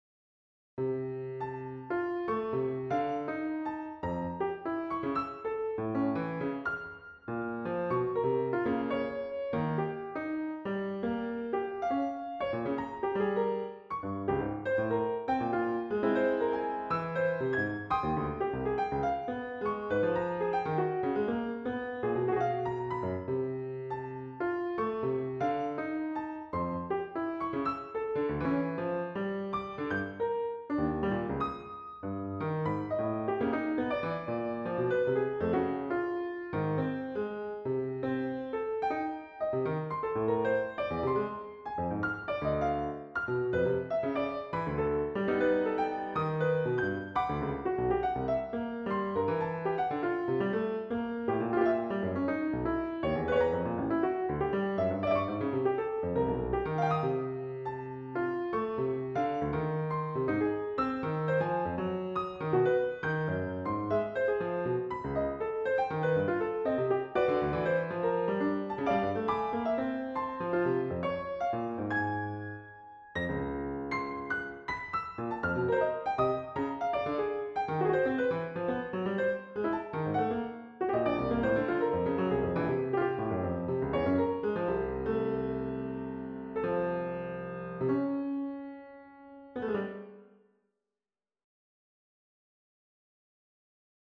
Instrumentation Piano solo